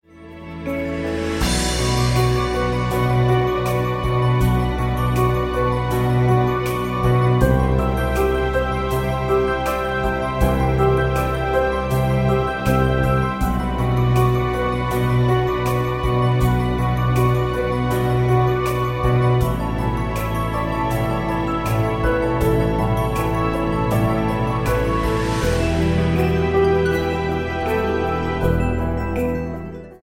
80 BPM
Acoustic piano floats over a soft bed.